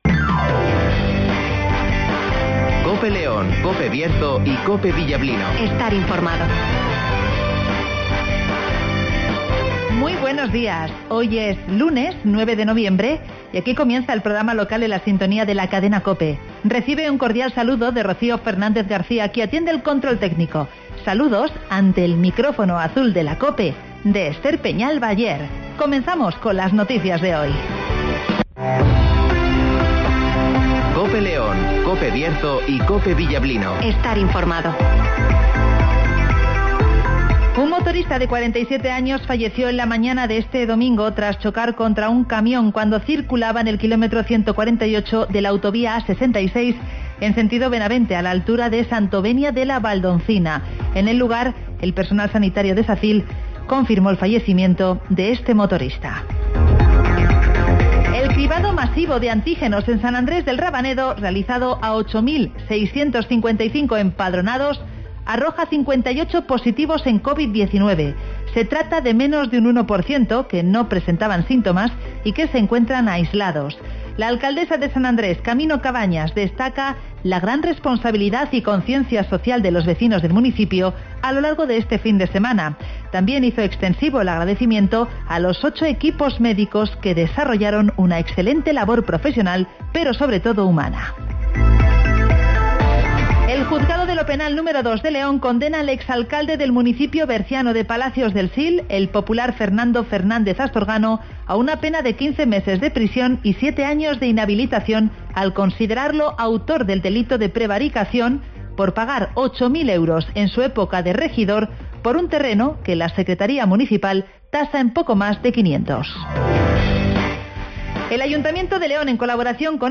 Avance informativo, El Tiempo (Neucasión) y Agenda (Carnicerias Lorpy)